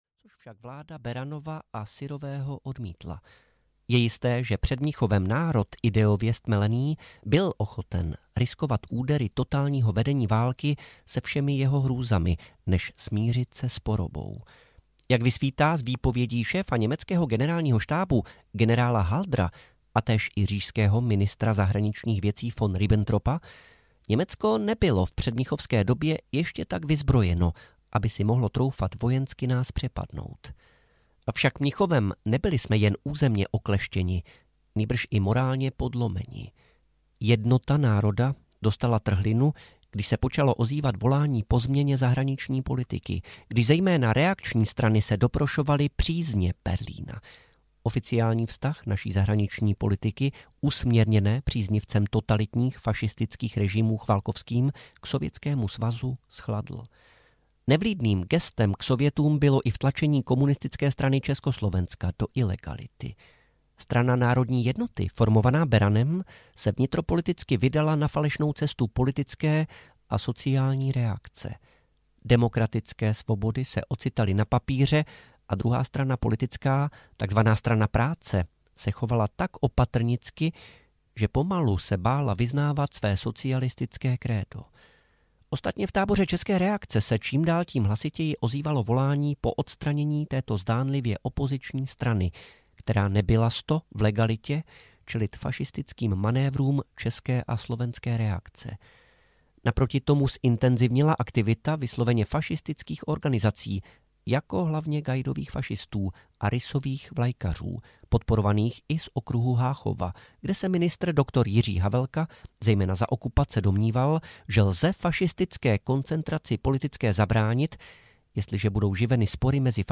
Hrdinové domácího odboje audiokniha
Ukázka z knihy